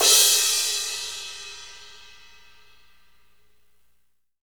Index of /90_sSampleCDs/Northstar - Drumscapes Roland/DRM_AC Lite Jazz/CYM_A_C Cymbalsx